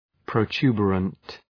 Shkrimi fonetik{prəʋ’tu:bərənt}